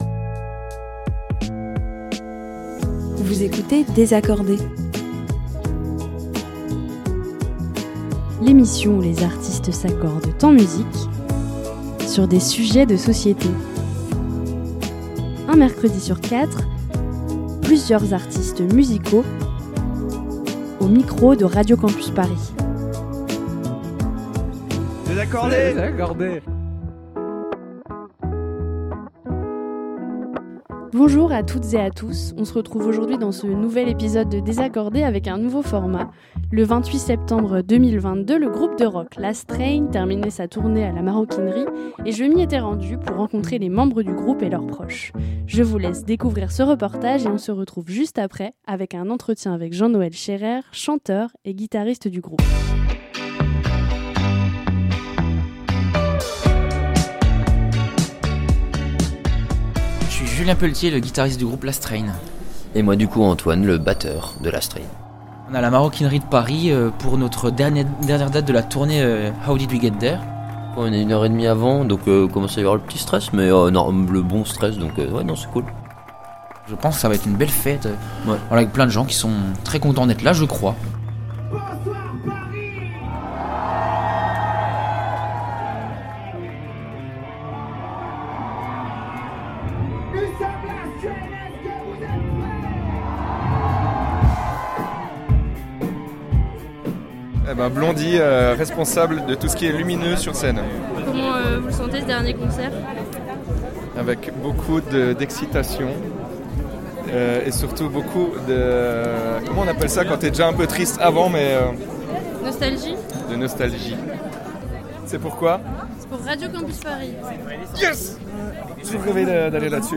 Vous entendrez en première partie d'émission, un reportage sur le dernier concert de leur tournée, en septembre 2022, à la Maroquinerie.